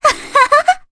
Cassandra-Vox_Happy3_jp.wav